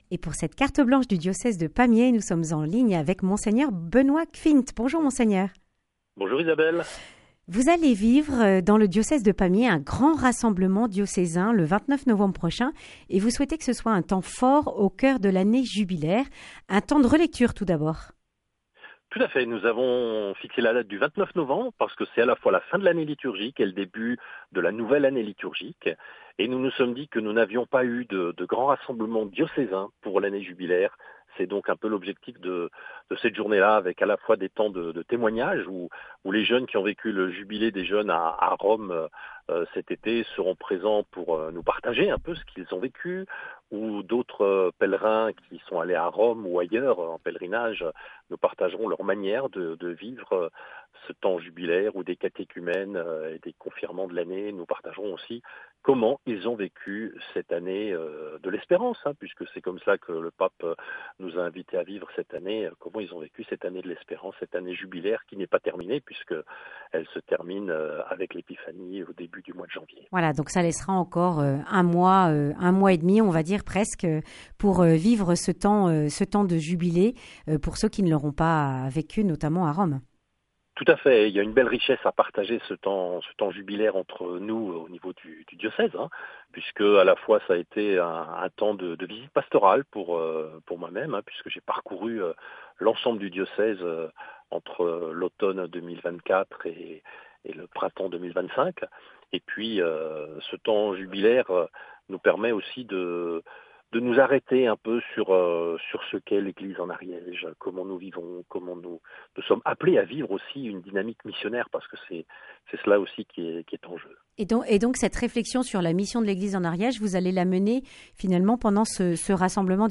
Monseigneur Benoit Gschwind, évêque de Pamiers, Couserans et Mirepoix, présente le rassemblement diocésain du 29 novembre.